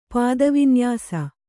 ♪ pāda vinyāsa